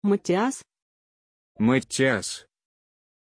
Pronunciación de Mattias
pronunciation-mattias-ru.mp3